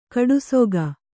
♪ kaḍusoga